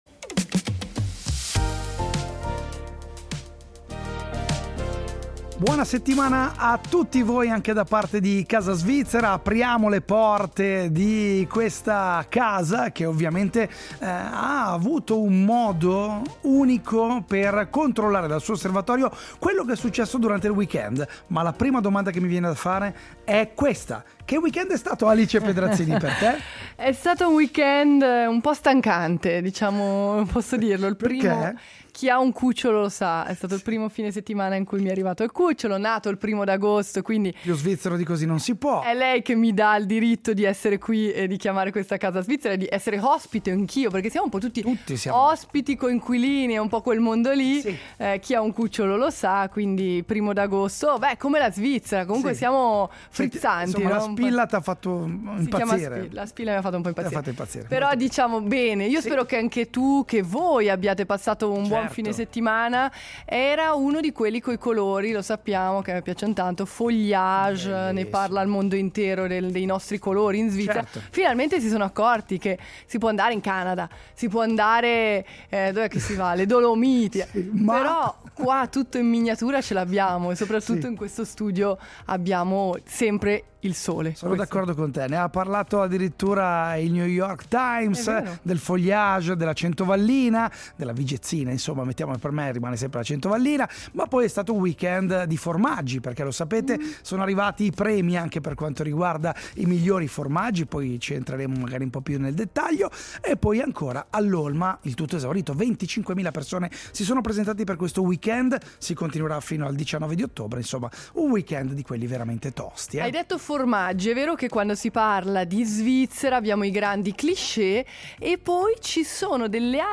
Oggi Casa Svizzera si è svegliata a Brusio, in Valposchiavo, dove abbiamo parlato di frutta e in particolare di quello più amato dagli svizzeri: la mela.